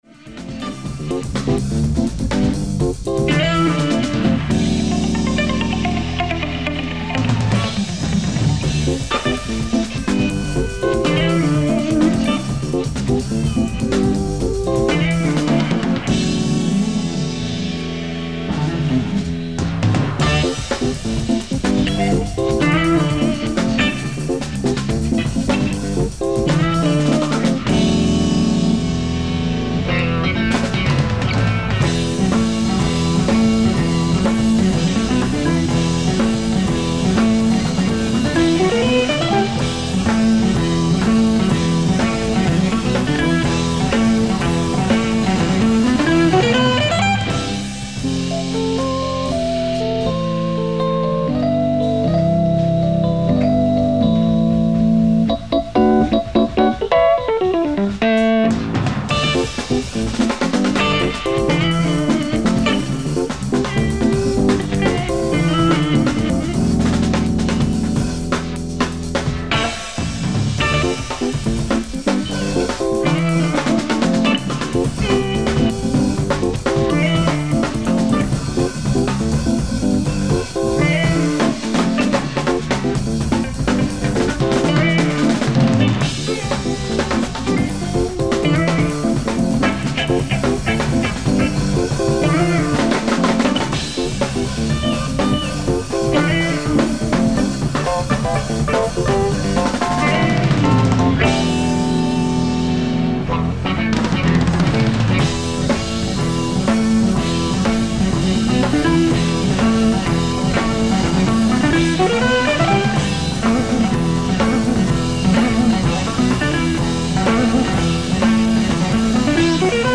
１９７５年、イリノイ州の有名クラブで行われたライブを 発掘された放送音源より初めて収録した大傑作ライブ音源盤です。